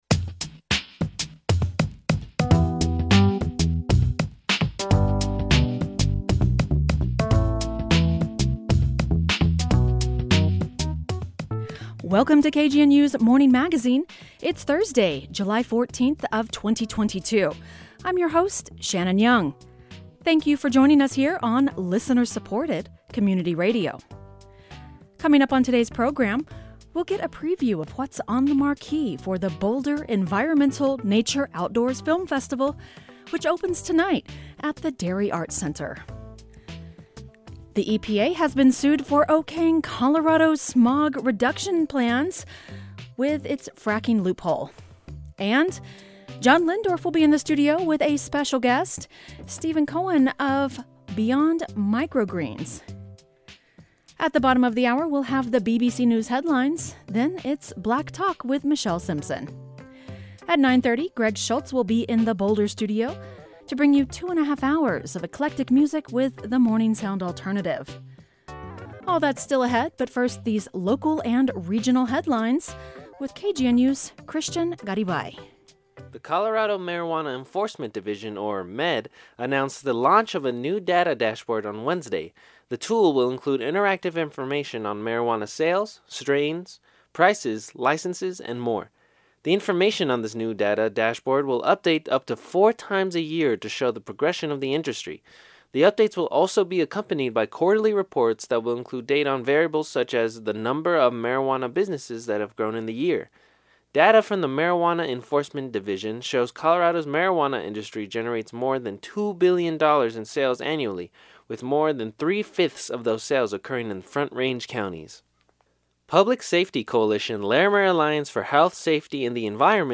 The Morning Magazine features local news headlines, stories, and features and broadcasts on KGNU Monday through Friday, 8.04-8.30 AM.